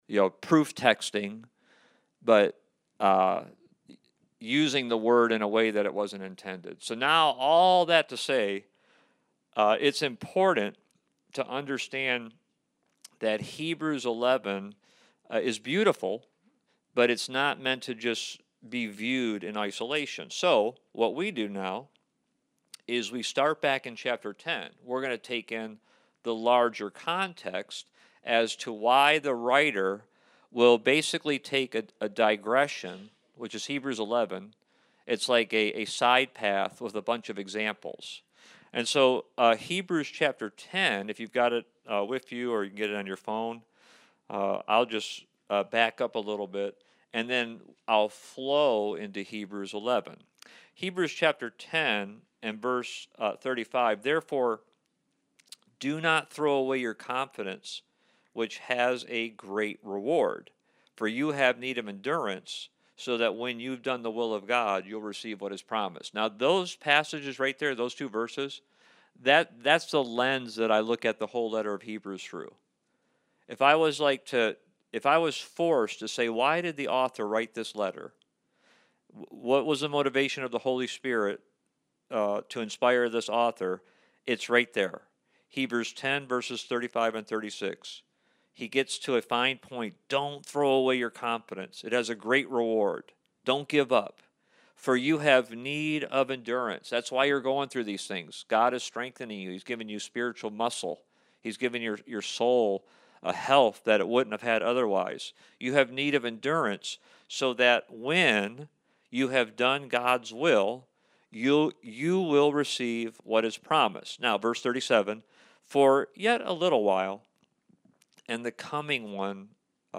Series: Study of Hebrews Service Type: Wednesday Night